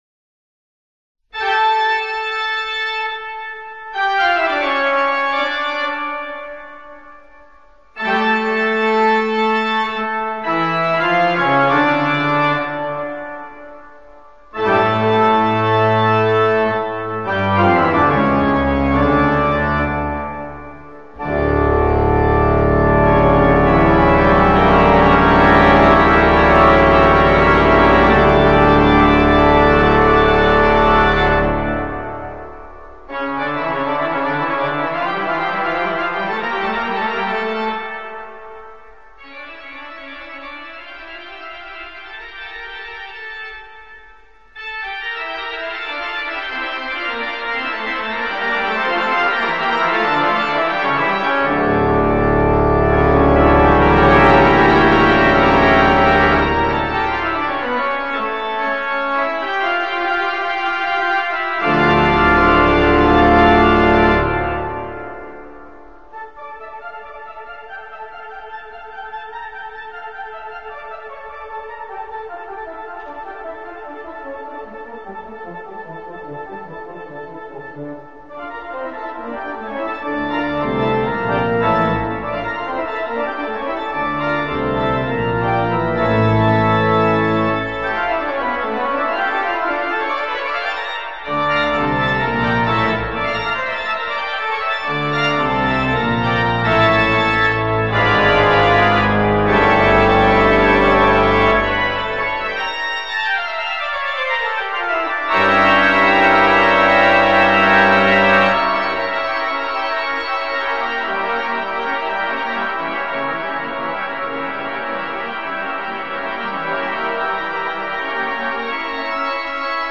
Orgue
0913_Toccata_et_fugue_en_re_mineur_Jean-Sebastien_Bach_Orgue.mp3